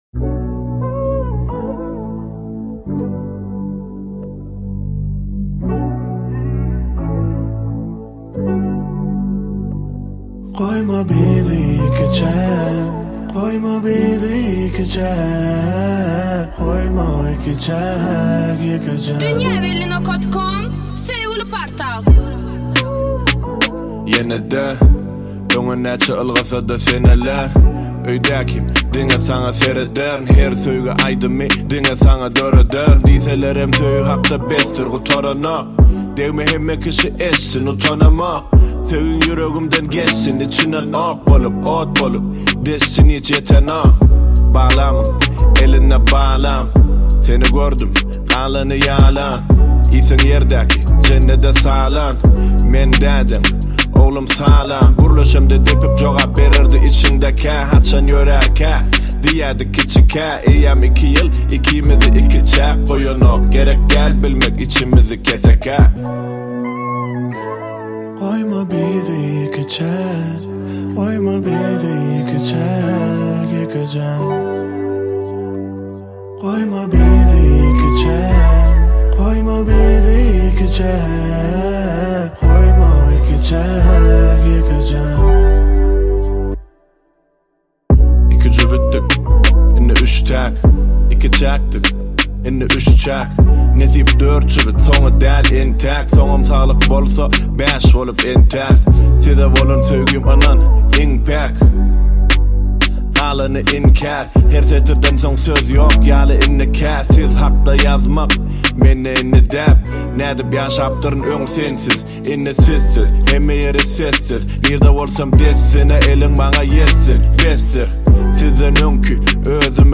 Bölüm: Türkmen Aýdymlar / Rep